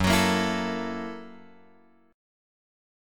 F#7#9 chord {2 x 4 3 5 5} chord